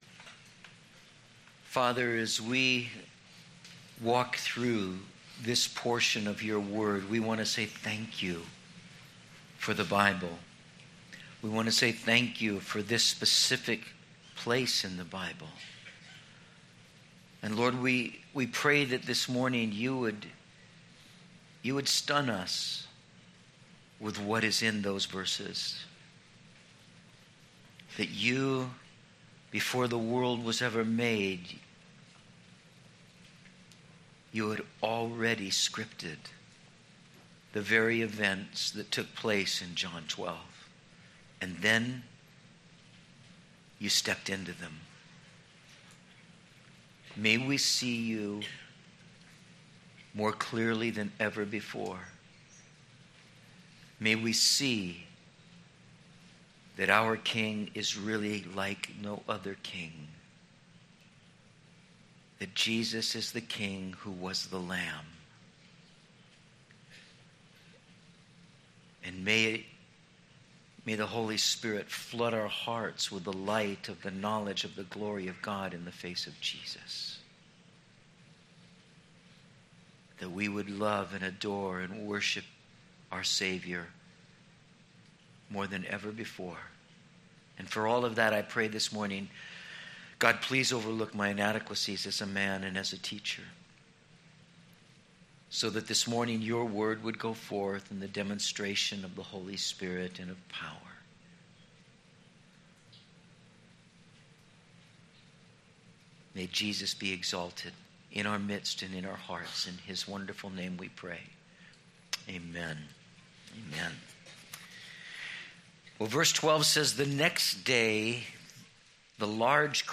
Content from Metro Calvary Sermons